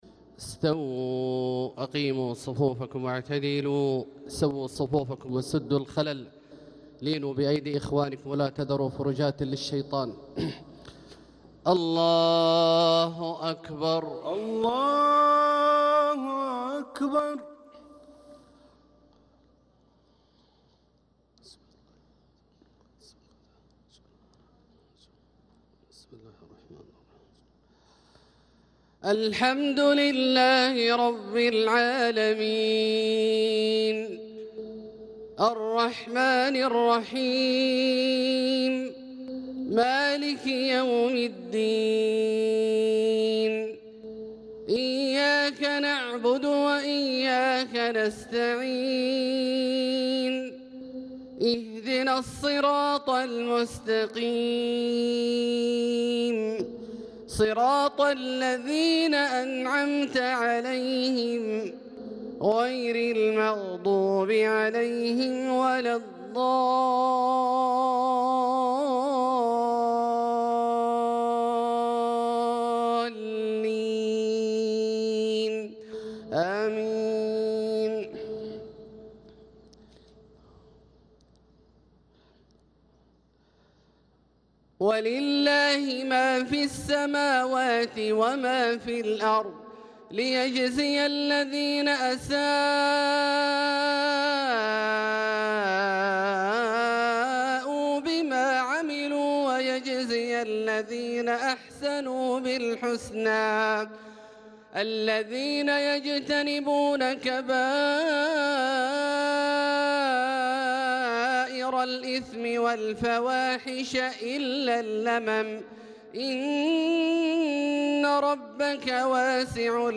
صلاة المغرب للقارئ عبدالله الجهني 6 ذو الحجة 1445 هـ
تِلَاوَات الْحَرَمَيْن .